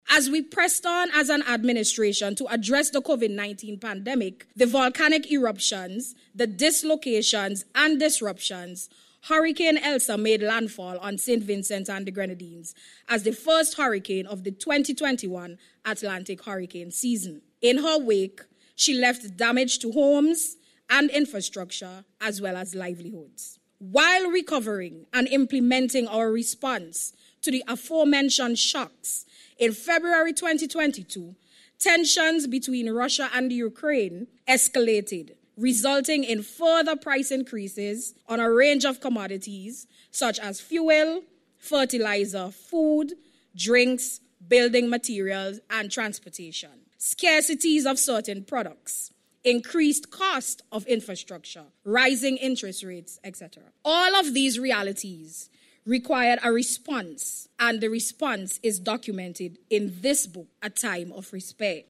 Peters made the remark last night while speaking at the launching ceremony for the book.